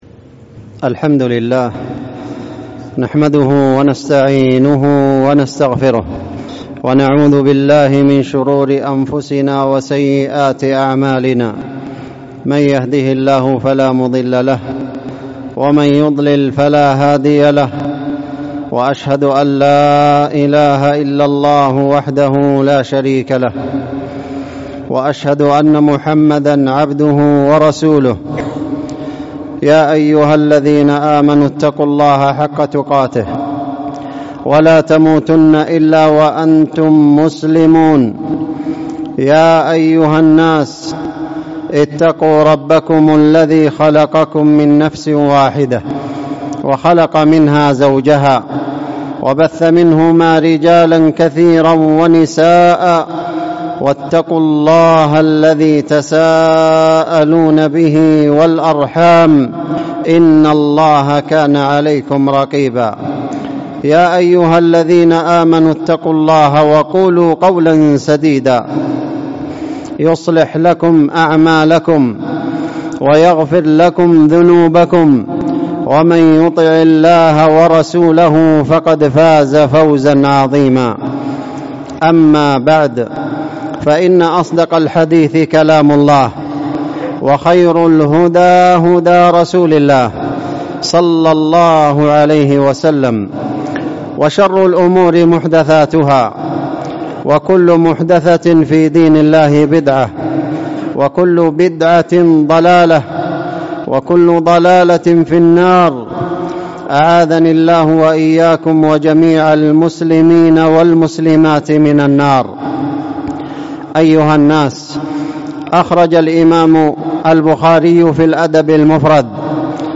ألقيت الخطبة بتاريخ 28 جمادى الآخرة بحضرموت - تريس - جامع بن زاهر